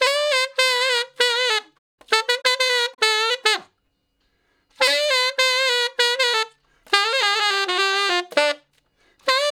066 Ten Sax Straight (D) 11.wav